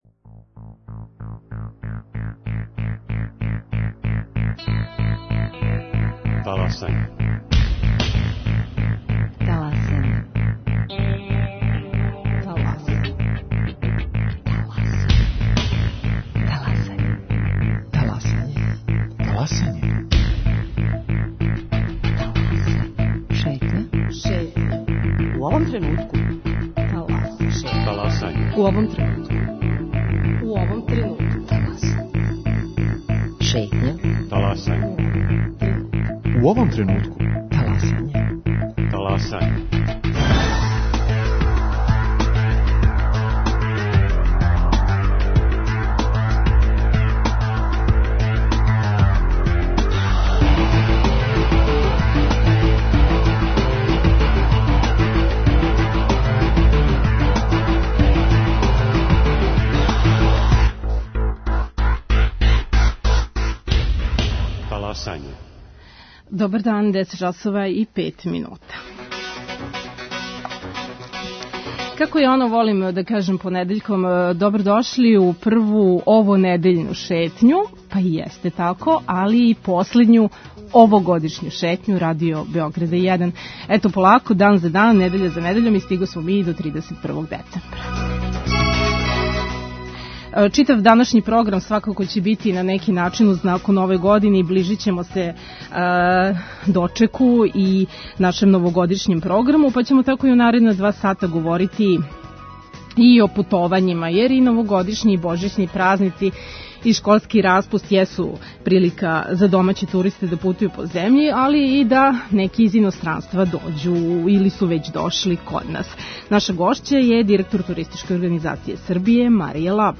Гост: Марија Лабовић, директор Туристичке организације Србије.